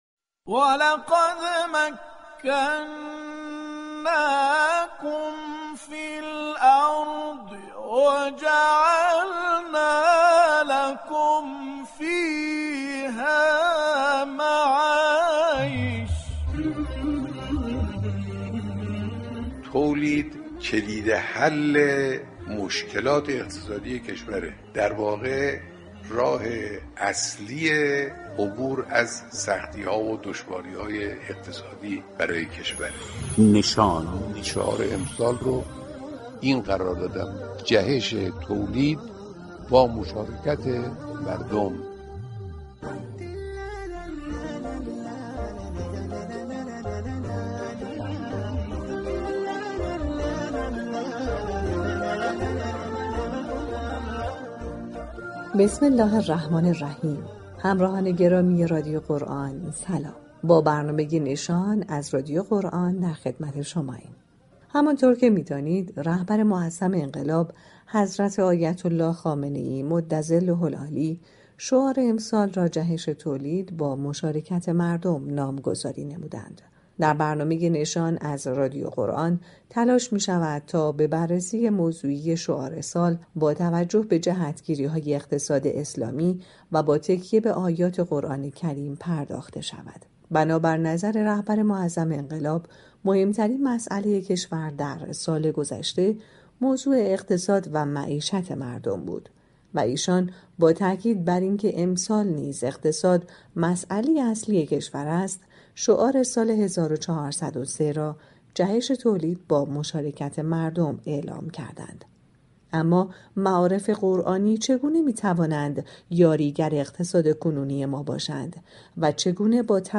نشان، عنوان میان‌برنامه شبكه رادیویی قرآن است كه به مدت 10 دقیقه با هدف تبیین شعار سال با عنایت به قرآن و روایات به روی آنتن این شبكه رادیویی می‌رود.